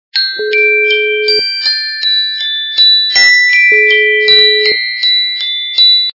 - звуки для СМС
При прослушивании Звук - Открывающаяся музыкальная шкатулка качество понижено и присутствуют гудки.
Звук Звук - Открывающаяся музыкальная шкатулка